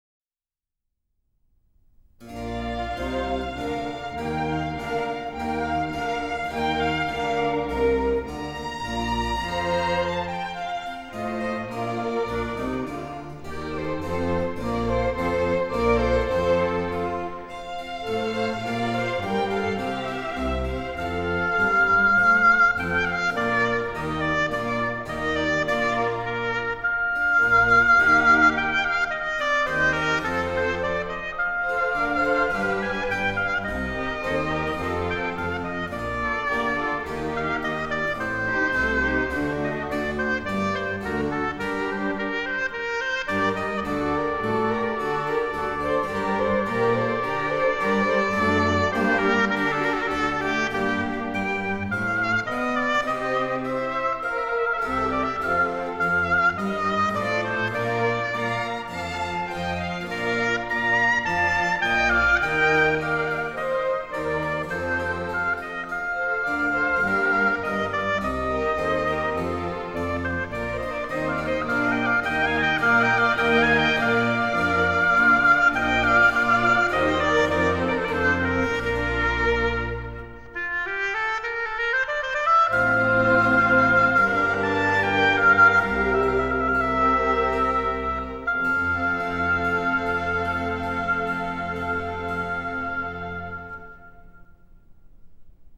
音樂類型：古典音樂
Concerto No.1 for Oboe in B flat major (HWV301)